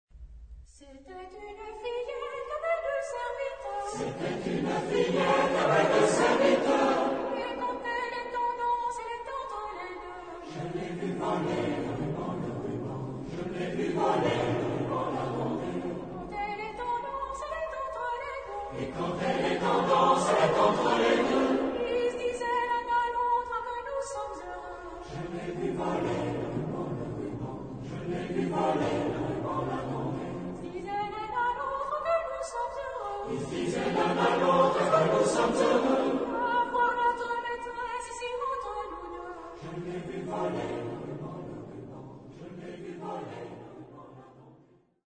Genre-Style-Forme : Profane ; Populaire
Type de choeur : SATB  (4 voix mixtes )
Tonalité : fa mode de ré
Origine : Acadie ; Canada